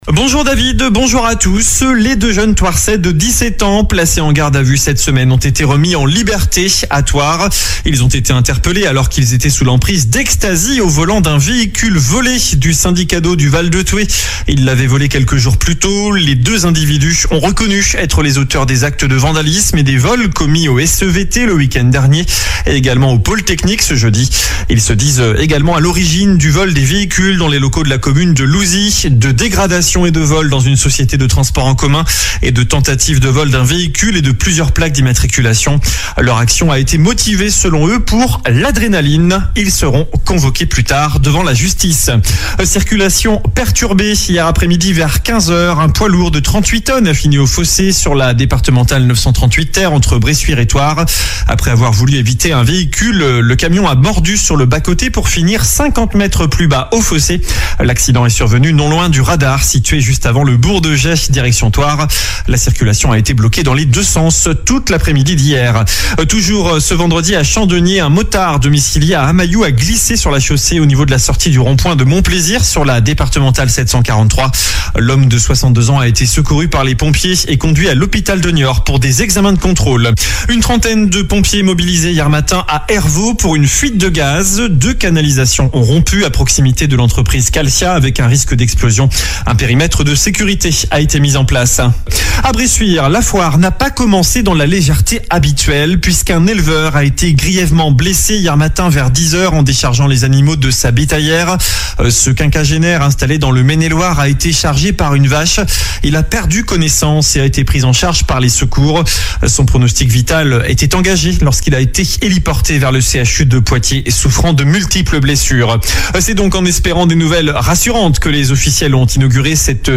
Journal du samedi 16 mars